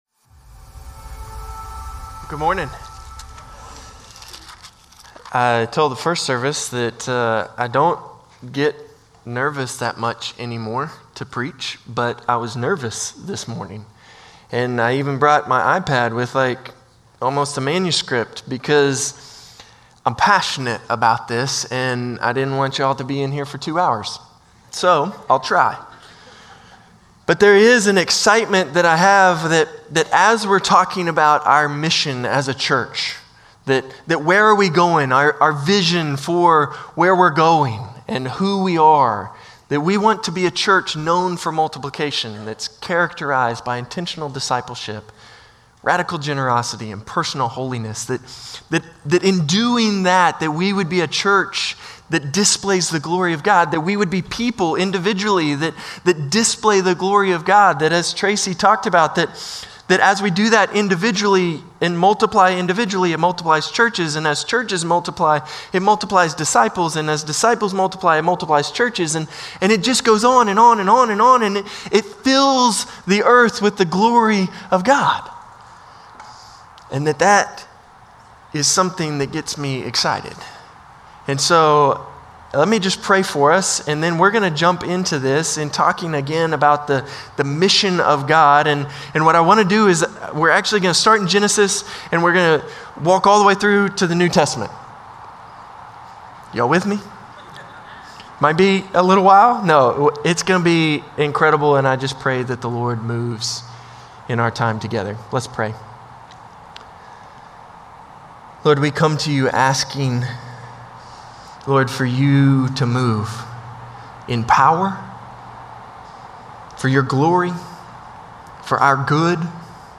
Norris Ferry Sermons June 8, 2025 -- Vision 2025 Week 2 -- 2 Timothy 2:2 Jun 11 2025 | 00:37:35 1x 00:00 / 00:37:35 Subscribe Share Spotify RSS Feed Share Link Embed